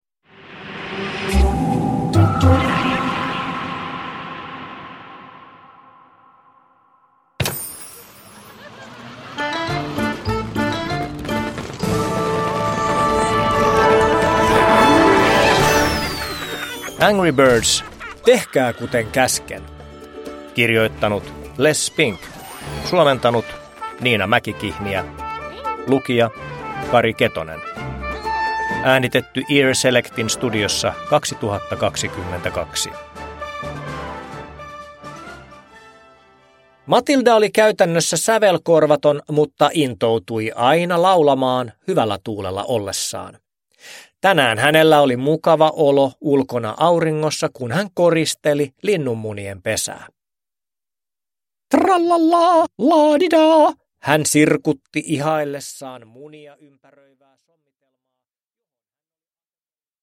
Angry Birds: Tehkää kuten käsken! (ljudbok) av Les Spink